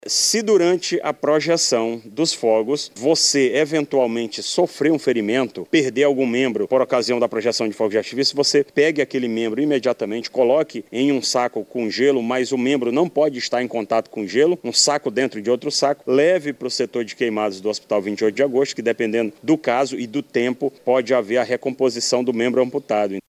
SONORA02_BOMBEIROS-1.mp3